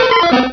pokeemerald / sound / direct_sound_samples / cries / poliwrath.aif